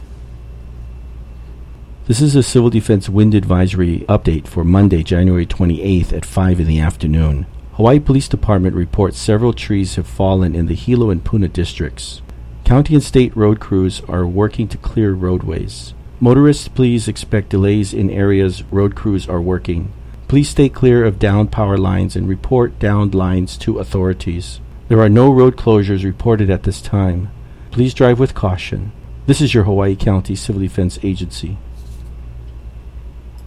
Hawaiʻi County Civil Defense issued an audio message (above).